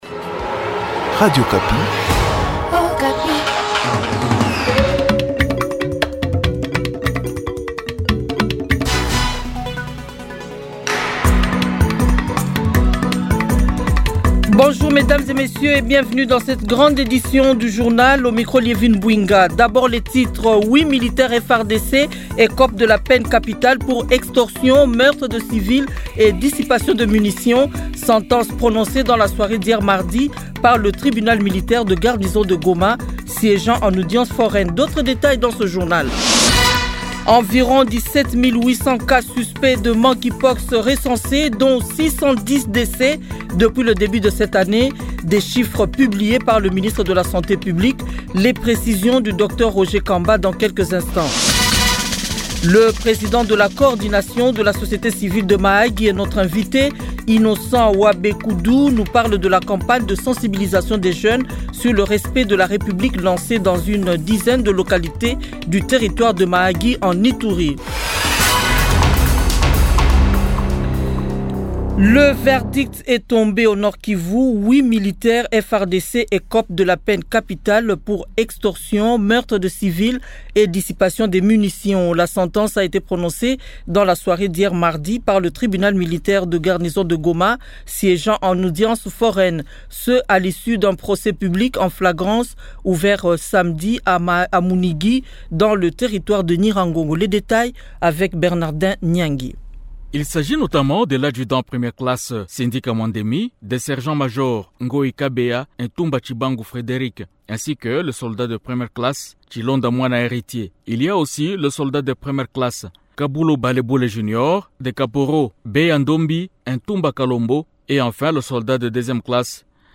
Journal Francais 15H